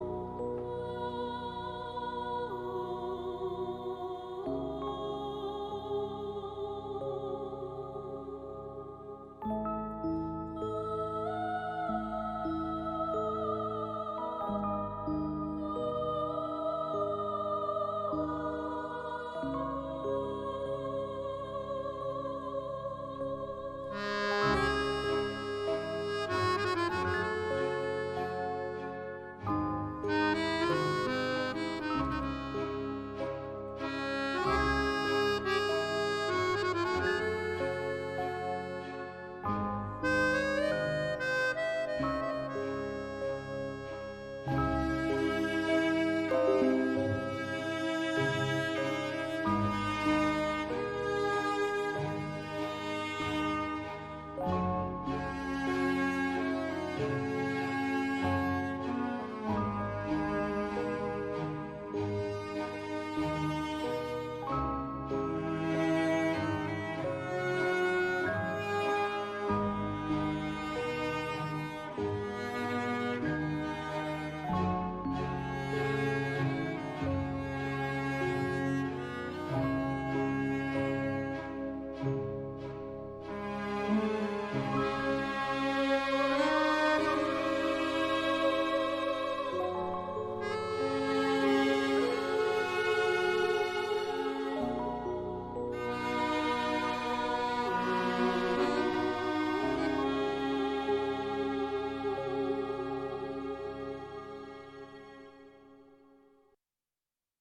تیتراژ فیلم سینمایی